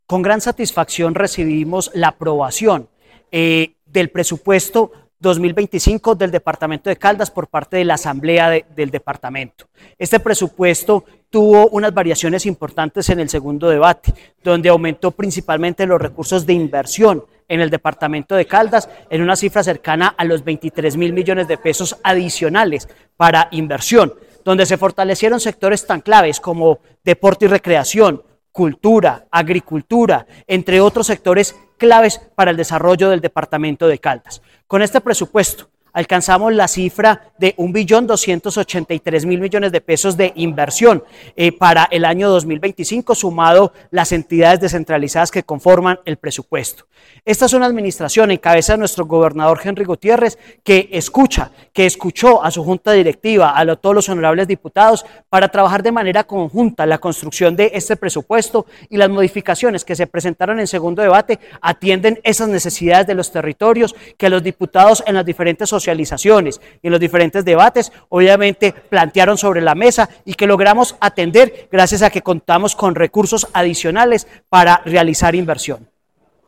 Jhon Alexander Alzate Quiceno, secretario de Hacienda de Caldas